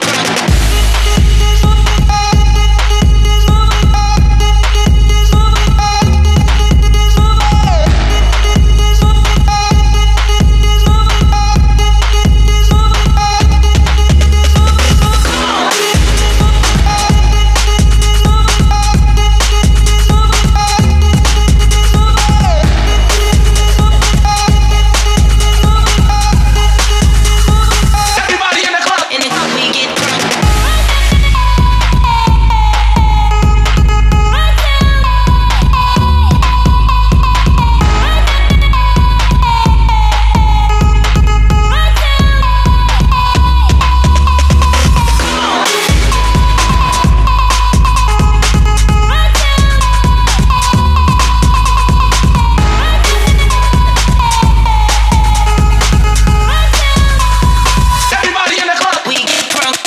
• Качество: 150, Stereo
качает
Electro Breaks
клуб
басовые
рингтон в стиле Electro Breaks